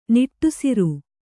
♪ niṭṭusiru